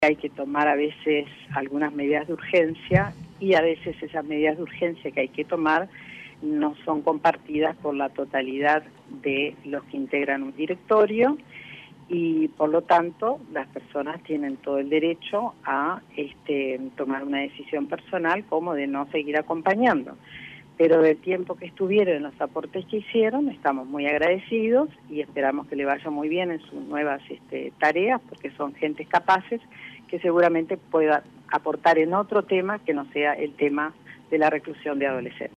Entrevistada por Rompkbzas, Fulco dijo que se debe "recimentar"; el sistema y para ello es necesario cambiar la forma de seleccionar a los funcionarios que trabajan en los centros de privación de libertad y crear una escuela destinada a la formación de estos educadores, una tarea que requiere "una alta especialización";.